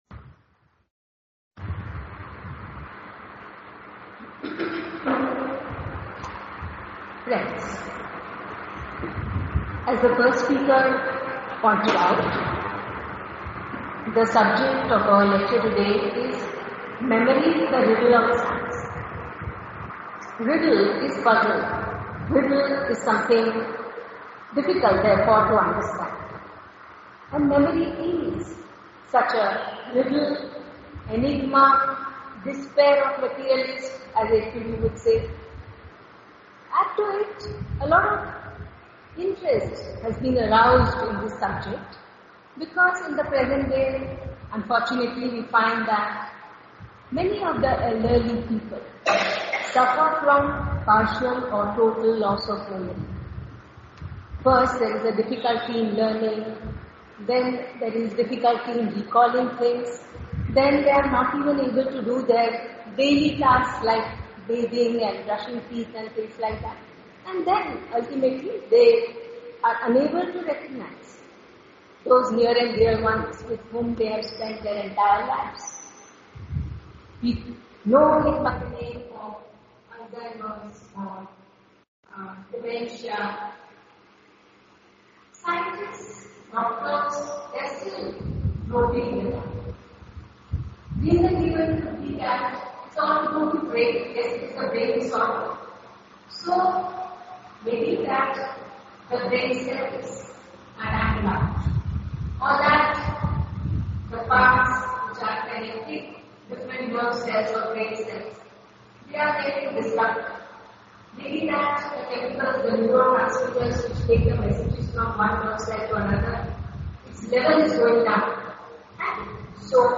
Uploaded Audio Lecture: Memory - The Riddle of Science